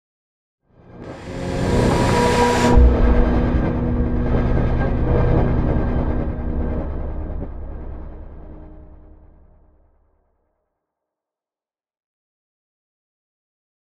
conjuration-magic-sign-circle-intro-fade.ogg